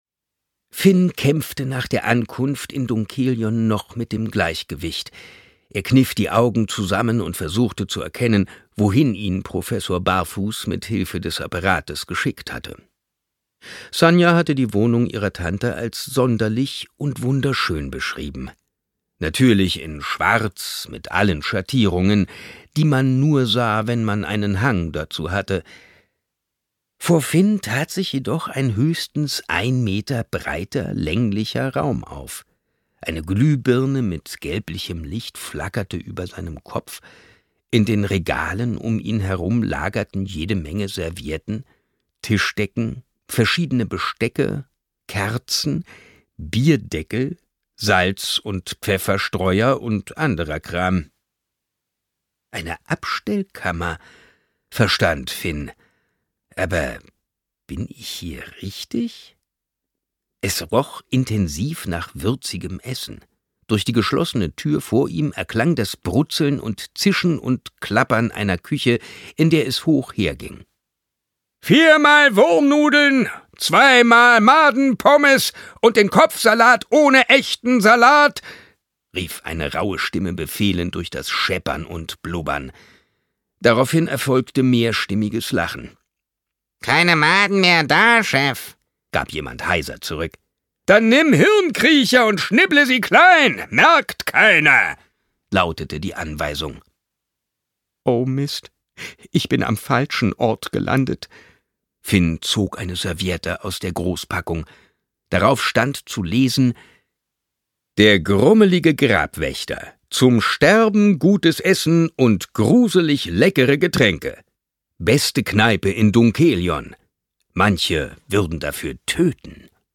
2025 | 1. Auflage, Ungekürzte Ausgabe